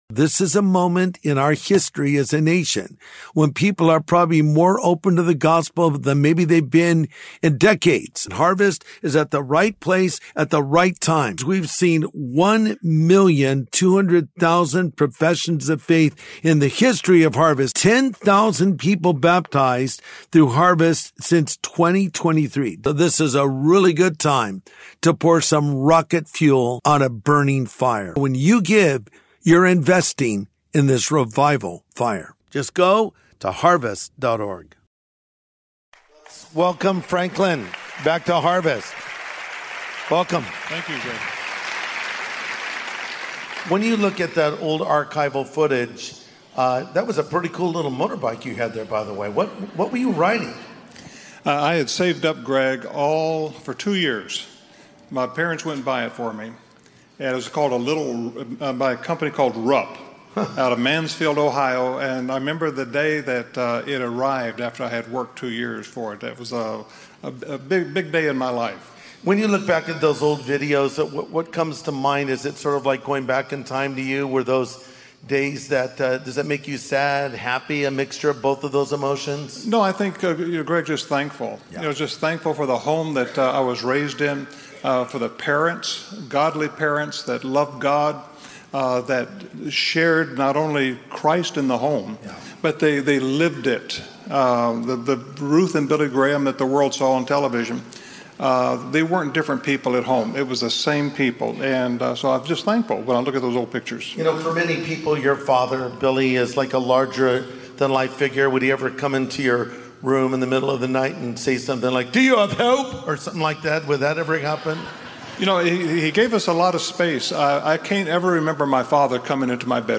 Christmas at the Graham House: An Interview with Franklin Graham
In a special episode, we revisit a conversation between Pastor Greg Laurie and Franklin Graham.